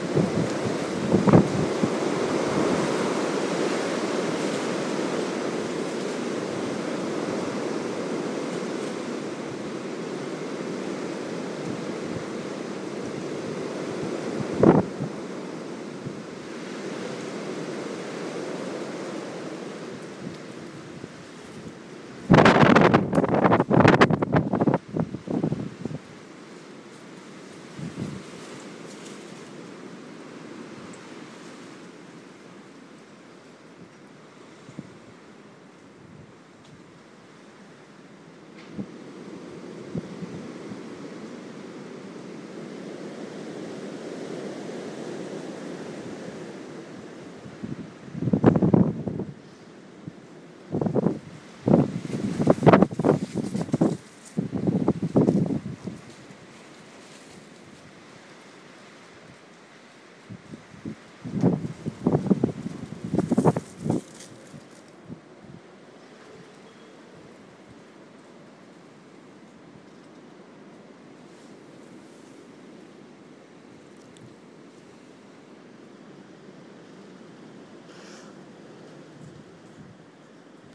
Harsh winds...
Harsh winds over Birmingham. 15.02.14.